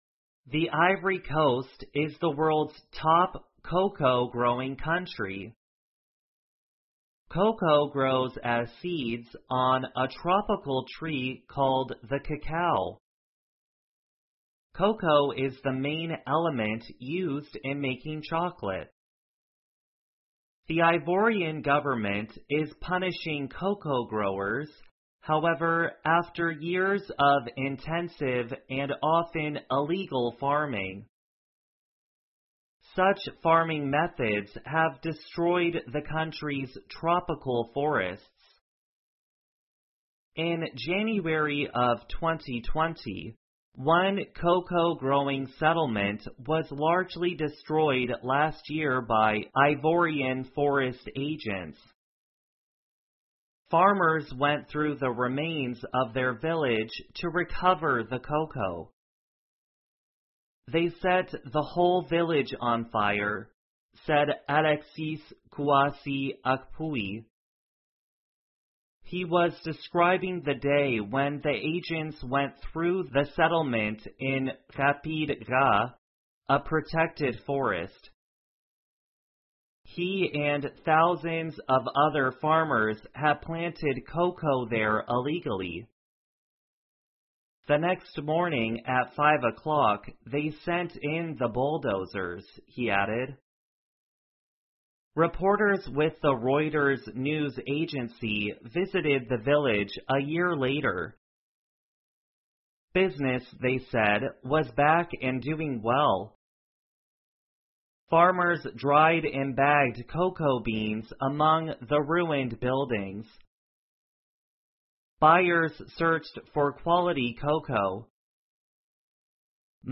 VOA慢速英语--象牙海岸为拯救被可可种植破坏的森林而展开的战斗 听力文件下载—在线英语听力室